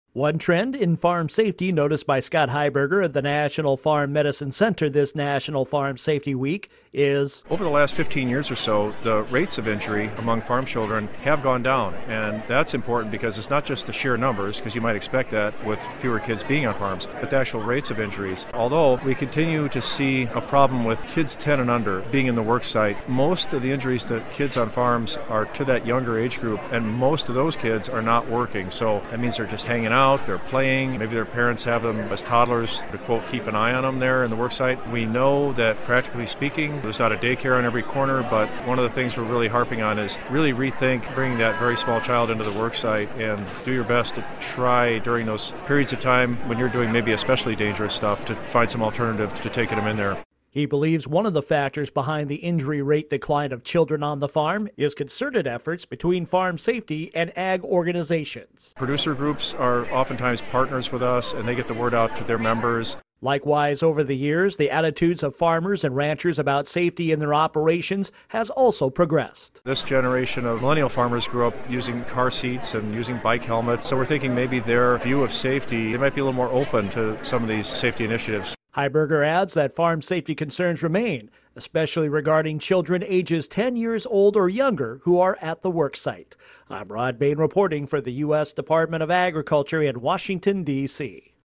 This is National Farm Safety Week (Sept 16 – 23).